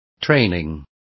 Also find out how preparaciones is pronounced correctly.